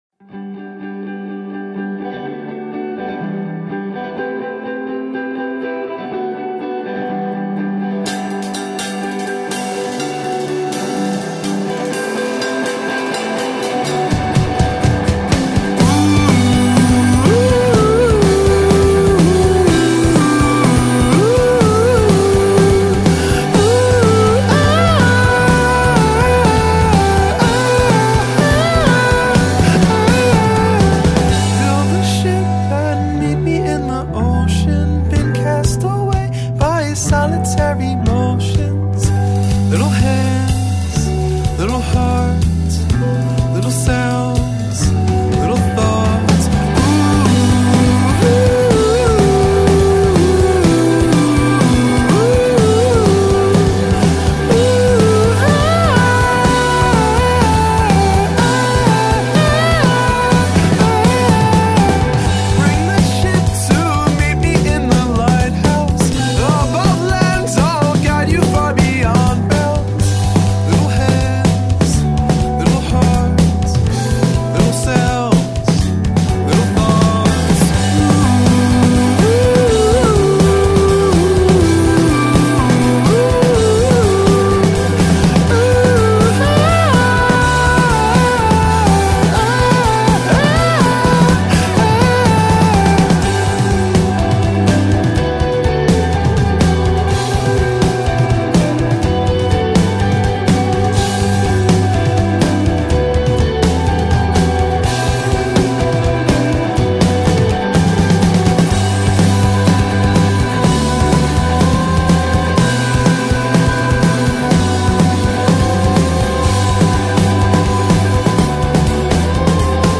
an indie-rock four piece out of Bensalem
vocals
bass
lead guitarist
drummer